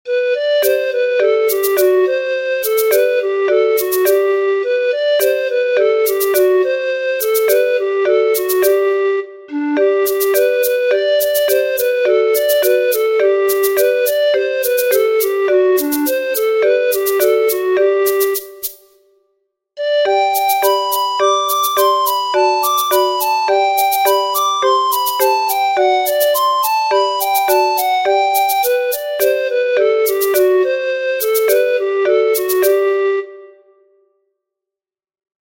Ciranda Cirandinha - Brazil
Ciranda, Cirandiha Arrangements (CC BY-SA)
The most commonly used time signature is binary.
These songs generally use percussion instruments such as the zabumba, tarol, ganzá, and maracas.